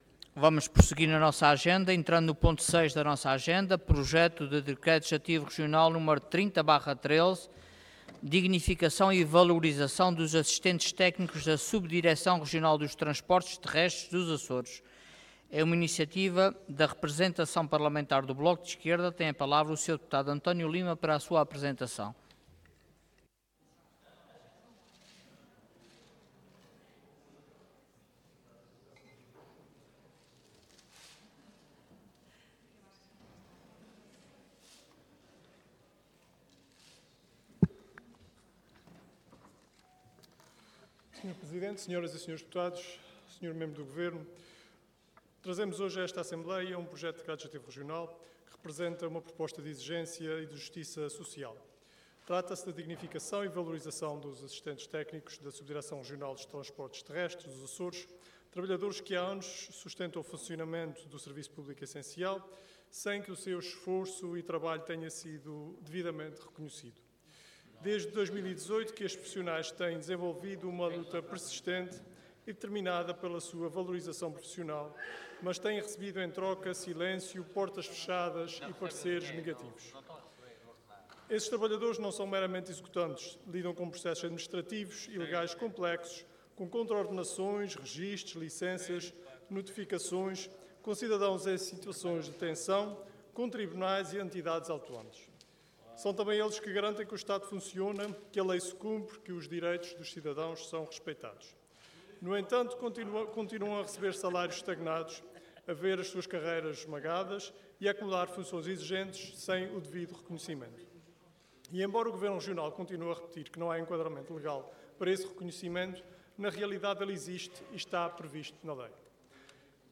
Intervenção
Orador António Lima Cargo Deputado Entidade BE